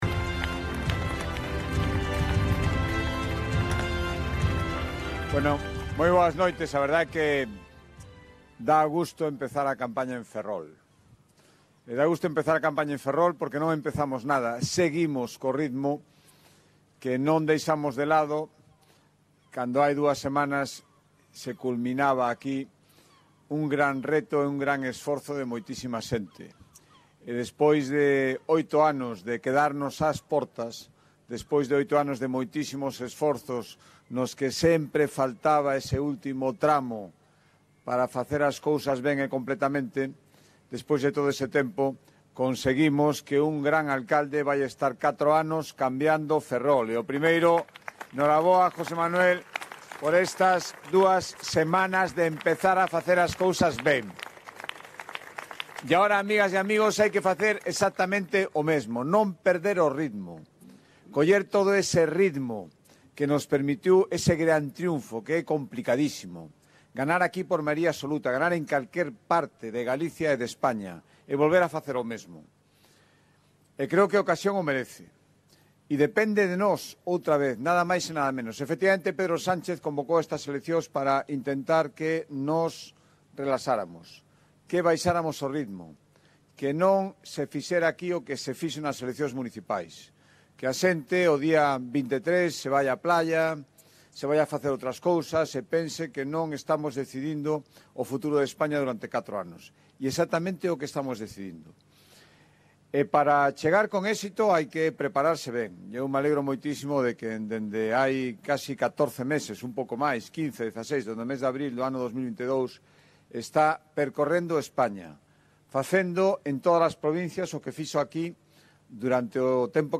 Acto del PP de inicio de campaña de las elecciones generales en Ferrol - Galicia Ártabra Digital